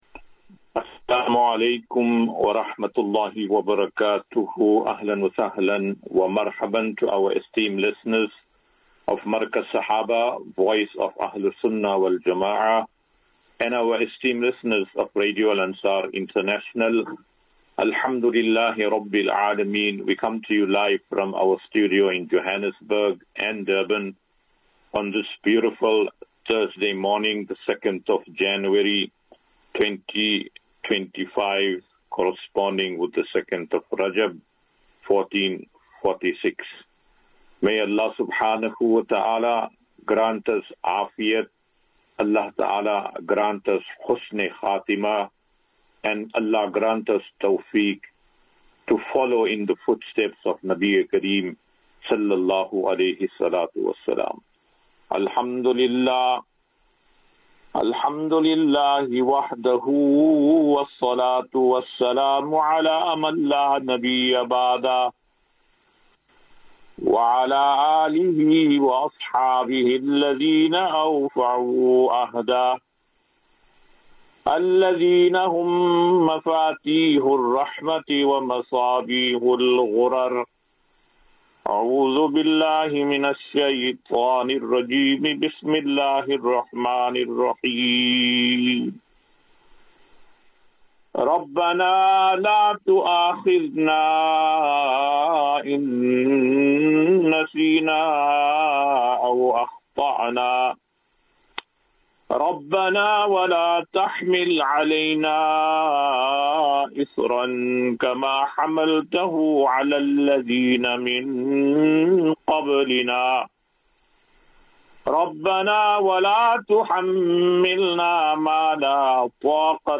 2 Jan 02 January 2025. Assafinatu - Illal - Jannah. QnA